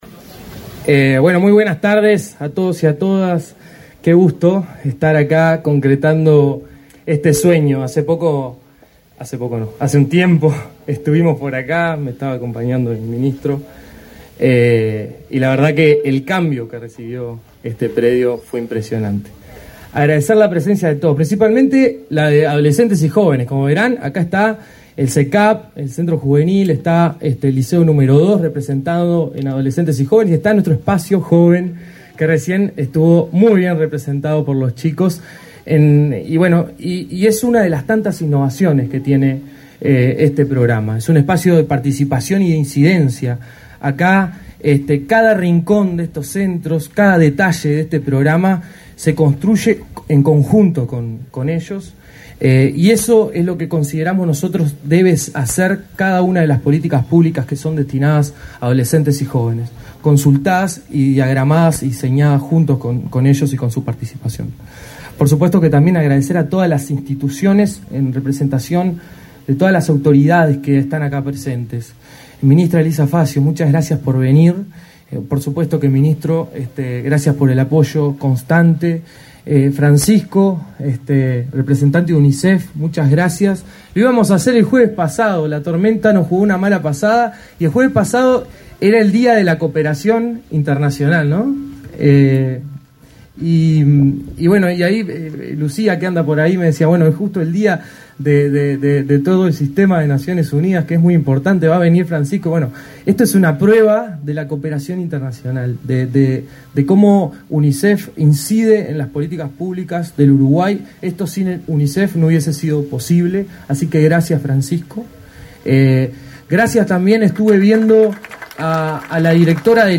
Acto de inauguración del centro Ni Silencio Ni Tabú, en la ciudad de Florida
Acto de inauguración del centro Ni Silencio Ni Tabú, en la ciudad de Florida 31/10/2024 Compartir Facebook X Copiar enlace WhatsApp LinkedIn El Ministerio de Desarrollo Social (Mides), a través Instituto Nacional de la Juventud (INJU), inauguró, este 31 de octubre, el centro Ni Silencio Ni Tabú, en la ciudad de Florida. En el evento disertaron el ministro Alejandro Sciarra, y el director del INJU, Aparicio Saravia.